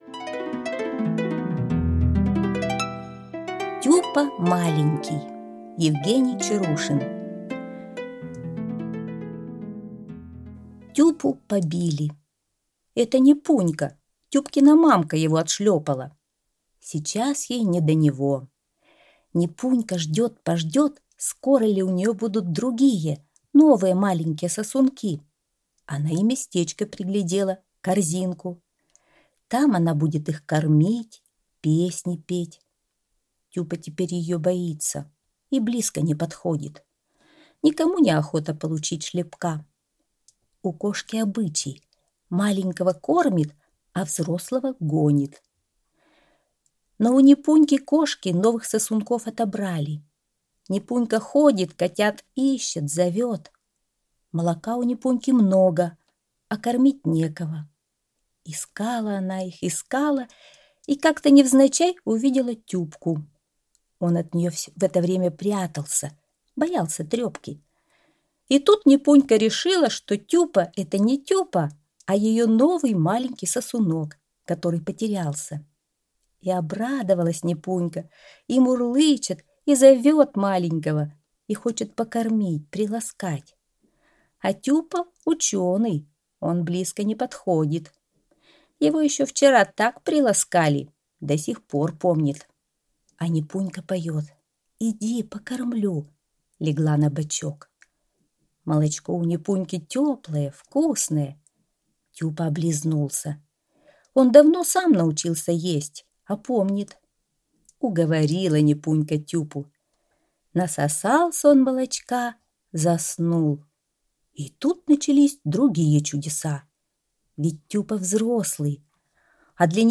Аудиорассказ «Тюпа маленький»